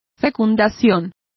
Complete with pronunciation of the translation of impregnations.